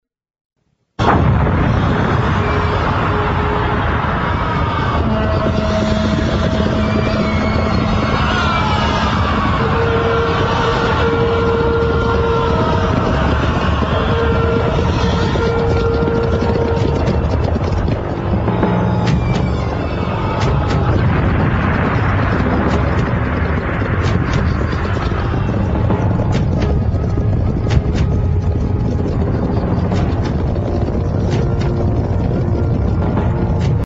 SFX战争场面开场音效下载
SFX音效